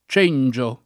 cengia [©%nJa o ©$nJa] s. f.; pl. ‑ge — raro cengio [